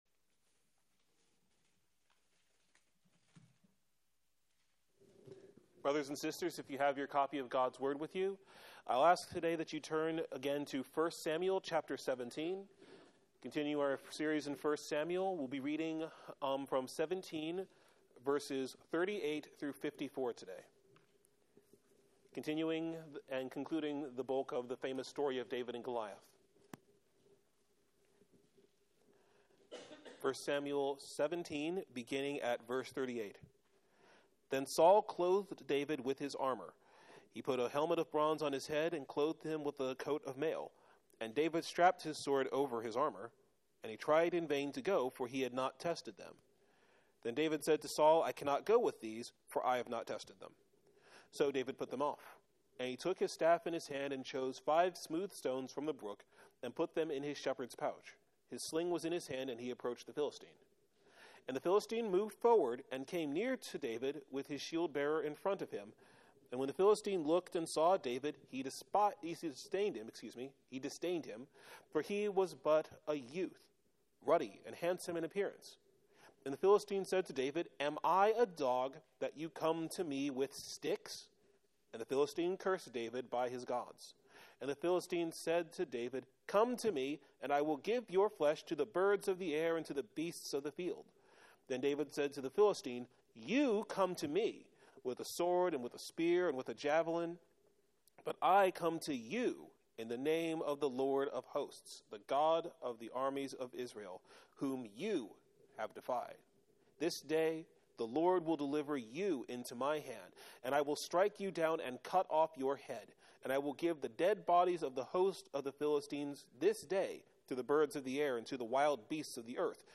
Sermon Text: 1 Samuel 17:38-54 Theme: The Lord of Hosts gave the worldly champion into the hand of His anointed one, proving that He alone is God and saves His people without the strengths of man.